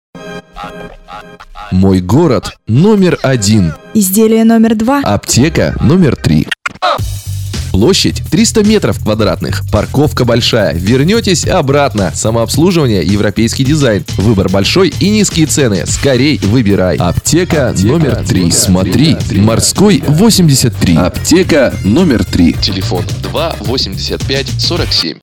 Радиоролик аптеки (сценарий) Категория: Копирайтинг